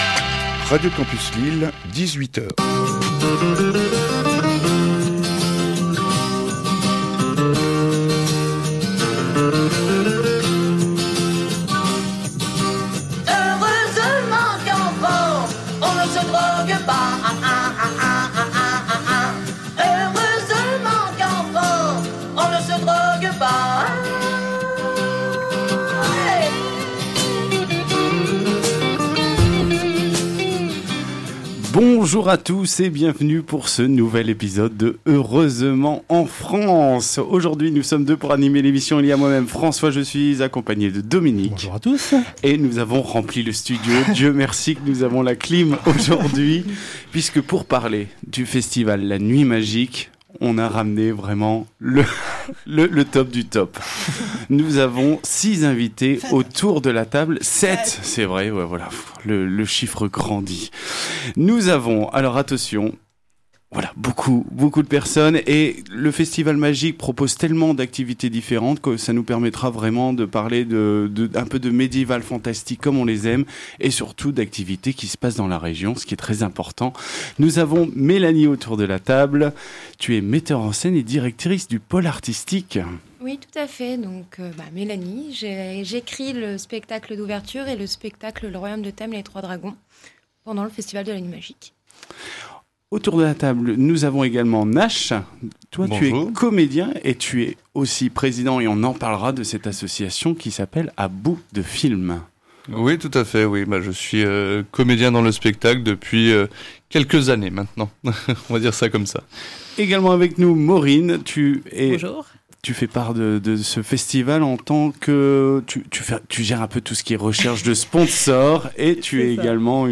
Au sommaire de cet épisode diffusé le 21 juillet 2019 sur Radio Campus 106,6FM :
– Le festival La Nuit Magique (du 3 au 10 août 2019 à Bailleul-lès-Pernes) – L’association A Bouts de Films – De la musique médiévale – Un petit bout du spectacle « en direct live » – et même quelques scoops !